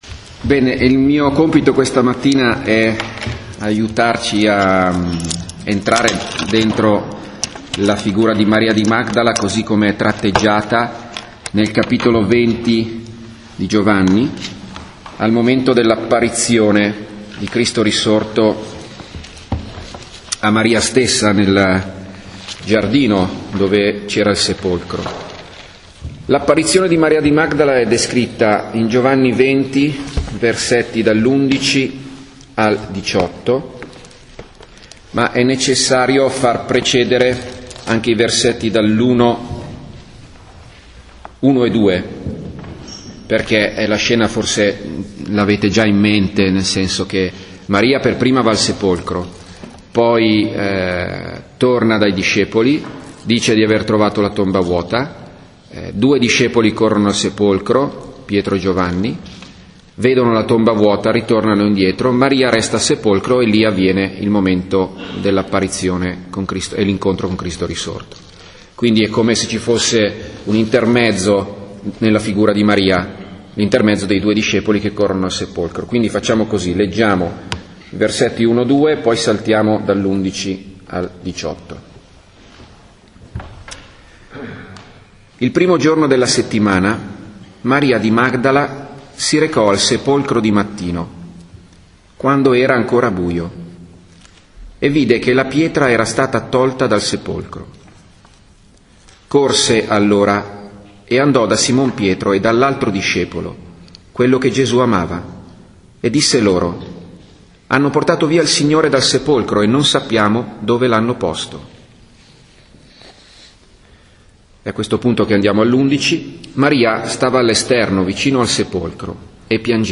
Gruppo biblico: Gv.20, 11-18